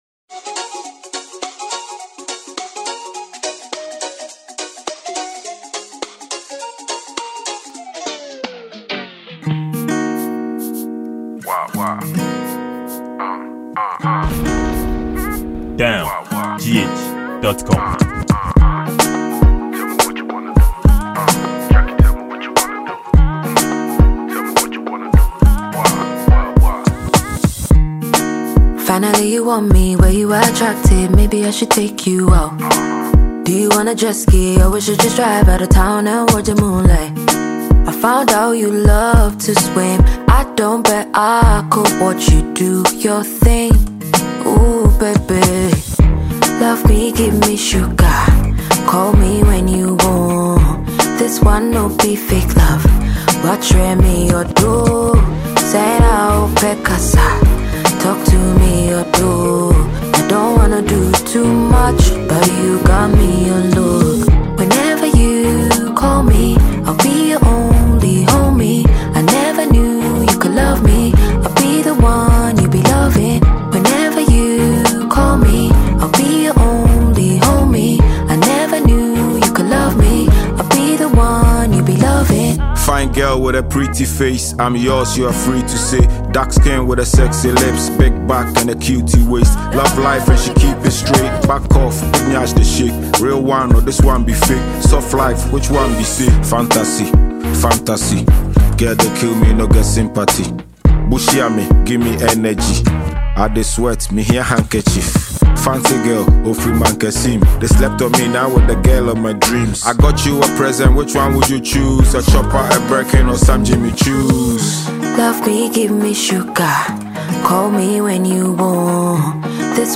2025 Ghana afrobeat song